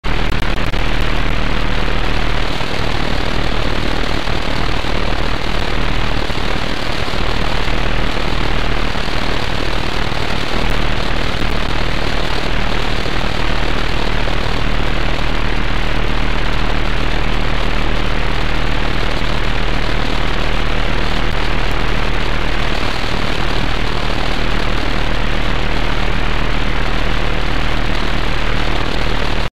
Вы можете прослушать и скачать записи работы станции: гул генераторов, щелчки антенн и другие технические шумы.
Звук советской загоризонтной радиолокационной станции Русский дятел